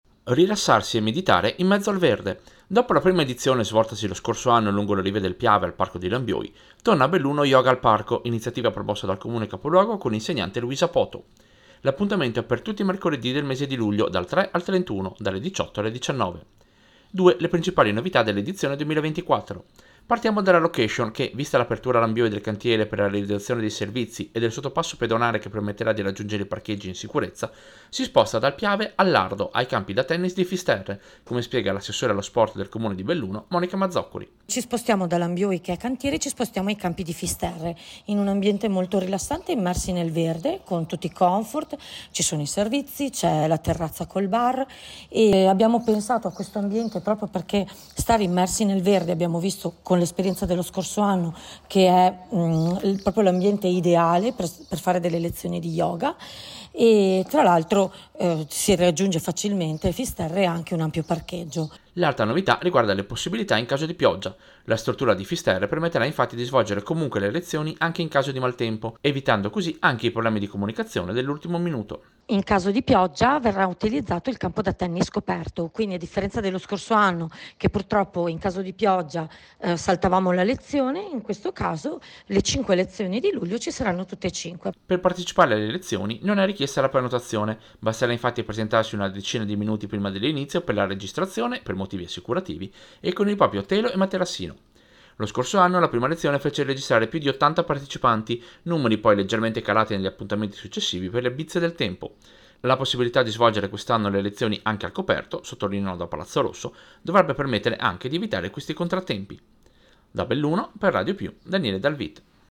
Servizio-Yoga-Fisterre.mp3